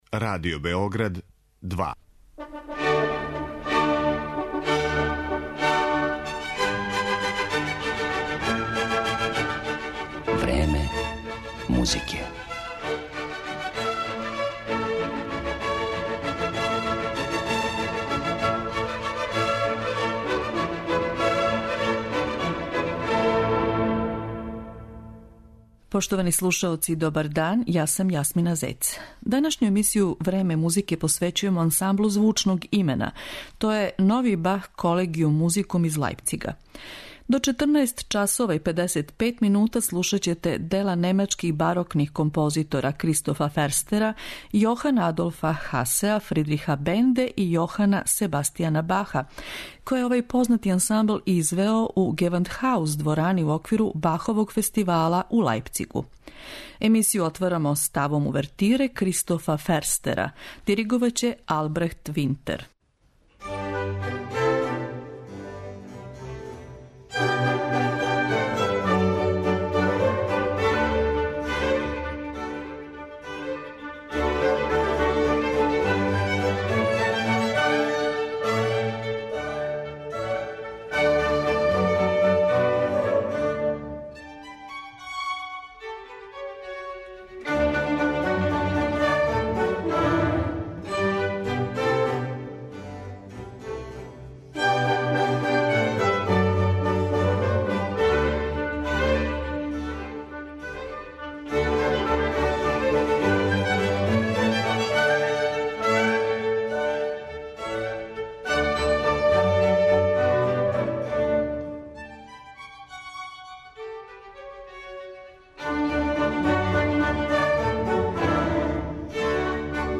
Eмитоваћемо снимке 'Новог Бах Колегијум музикум оркестра', који је прошле године у Лајпцигу, у чувеној 'Гевандхауз' дворани, извео дела немачких барокних композитора.
Овај реномирани ансамбл ужива велики углед међу оркестрима специјализованим за извођење барокне музике.